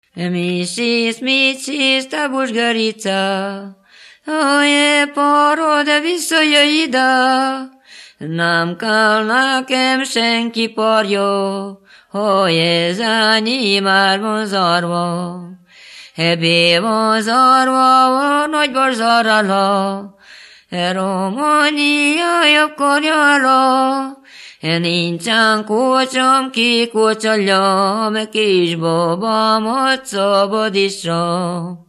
Moldva és Bukovina - Moldva - Klézse
ének
Stílus: 8. Újszerű kisambitusú dallamok
Szótagszám: 9.9.8.8
Kadencia: 2 (1) 1 1